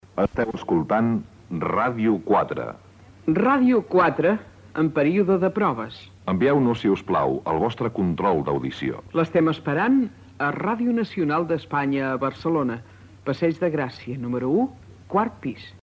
Identificació de l'emissora en període de proves i demanda de controls de recepció.
FM